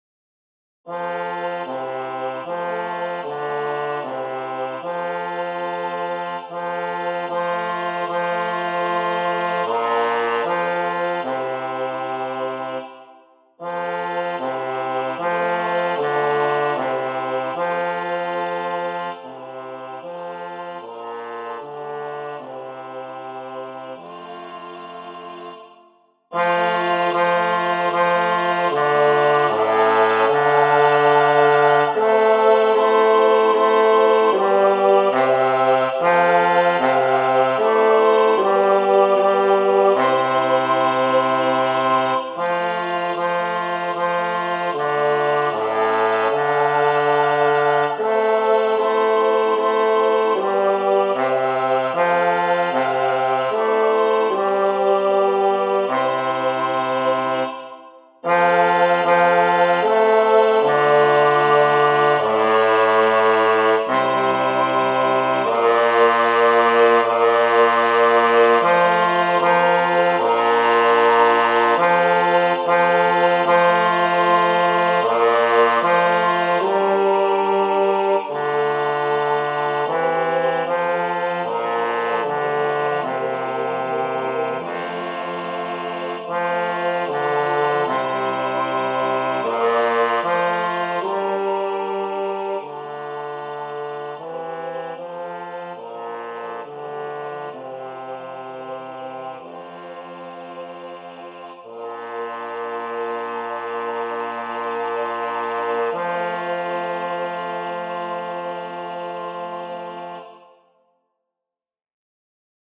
旧：Ave Maria(アルカデルト作曲)　音取り音源（練習では2/19配布のコーリューブンゲン版の方でやります） Ave Maria(アルカデルト)Sop.mp3　Ave Maria(アルカデルト)Alt.mp3　Ave Maria(アルカデルト)Ten.mp3